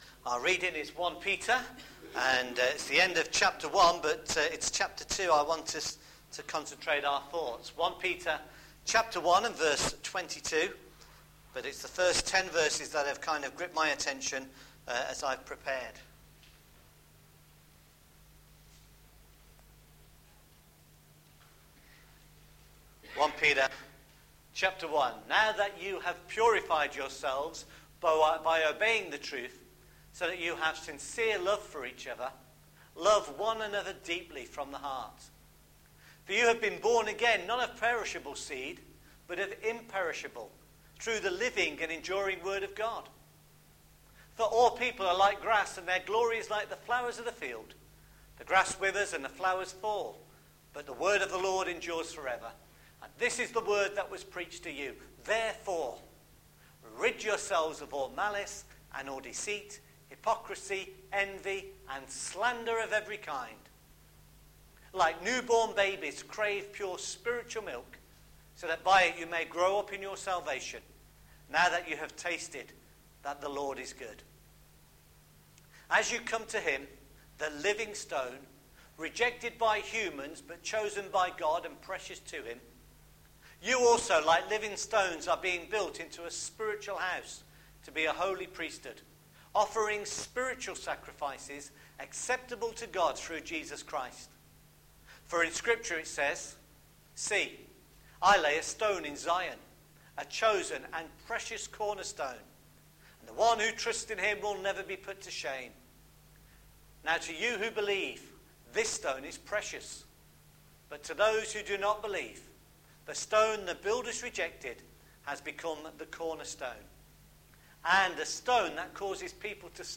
1 Peter chapter 2 verses 1-10 – sermon